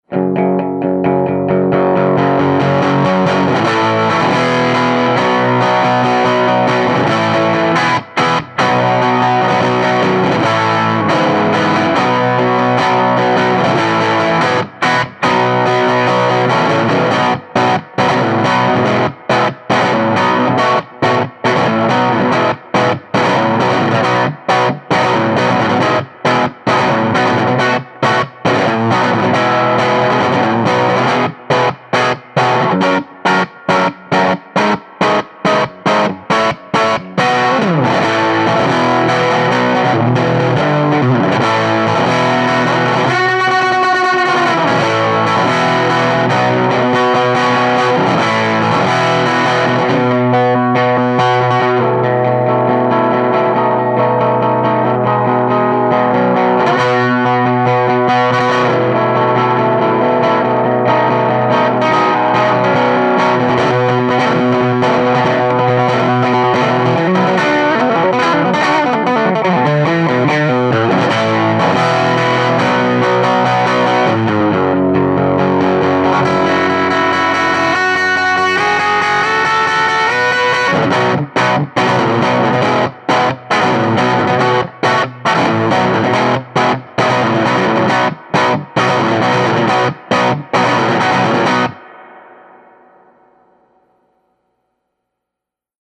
5W Class A - Single-Ended - 6V6 or 6L6 - Tube Rectified ~ ALL NEW Triode REVERB ~ 14lbs
Sunburst • Tele • Medium Gain   1:36